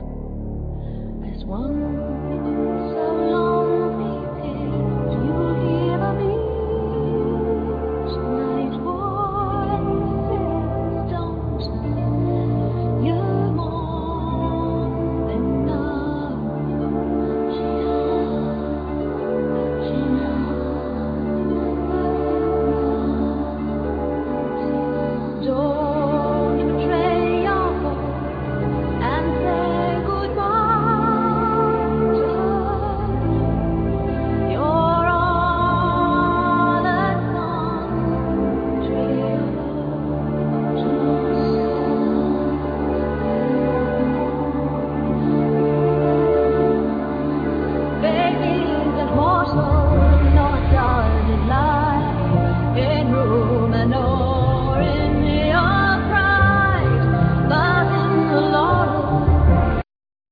Vocal,Mandolin,Firesticks,Bells,Chimes,Keyboards
Keyboard,Bass,Tablas,Angel harp,Effects
Percussions
Oboe